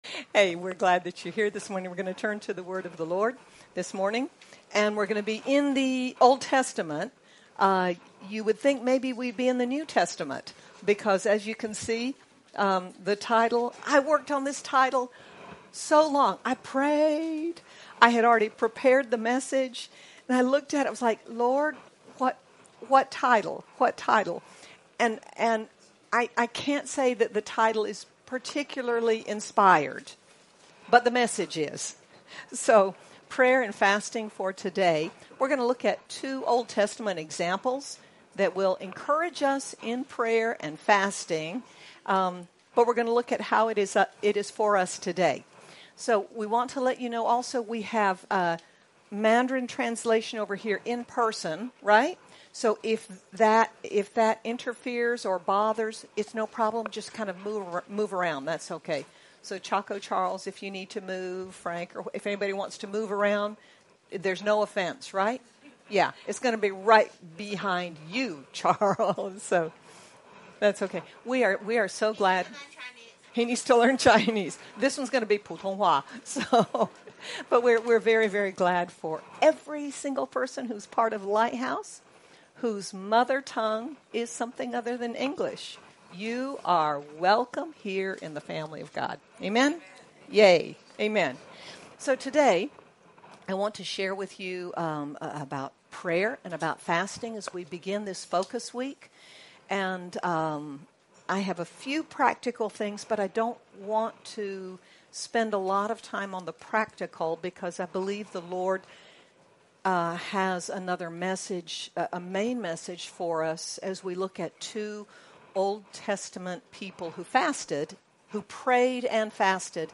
Daniel and Ezra show us how prayer and fasting help us know God’s plans and timing and bring His hand of strength and protection on our lives. Sermon by